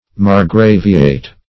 Search Result for " margraviate" : The Collaborative International Dictionary of English v.0.48: Margravate \Mar"gra*vate\, Margraviate \Mar*gra"vi*ate\, n. [Cf. F. margraviat.] The territory or jurisdiction of a margrave.